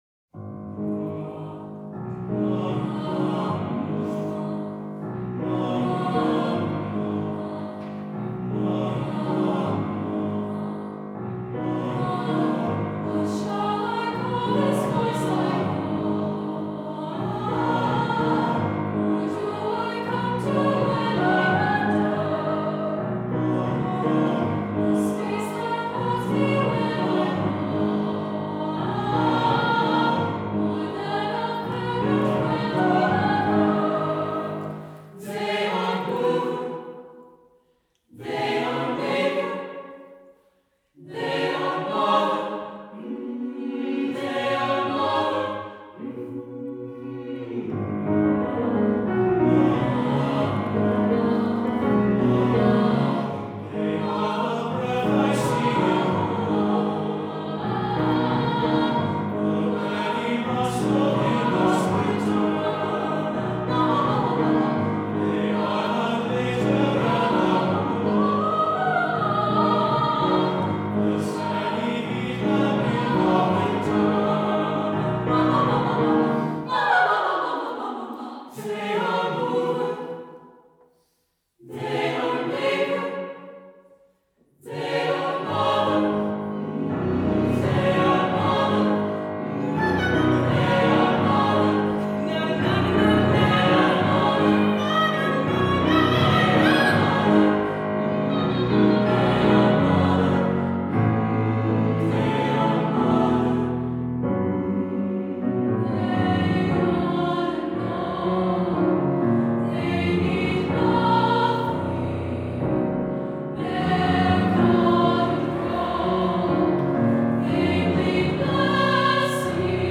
SSAA div. choir, SA solo group, and piano